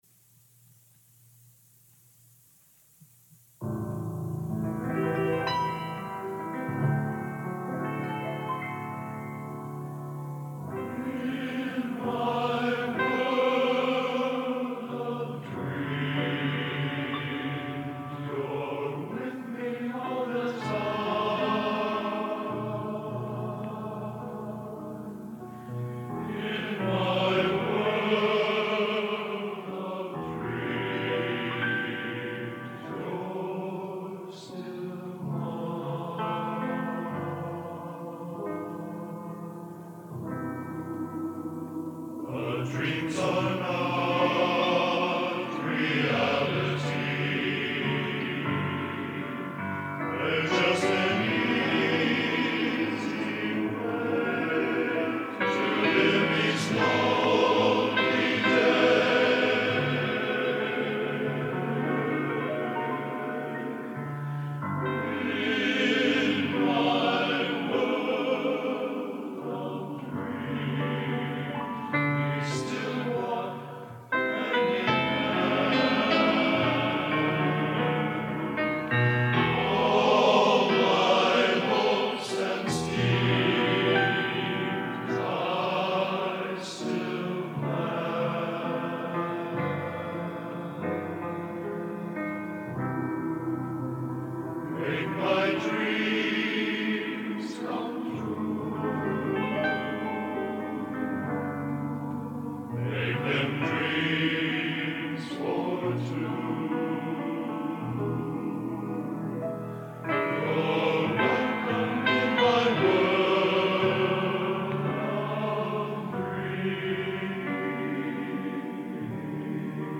Genre: Popular / Standards Schmalz | Type: Studio Recording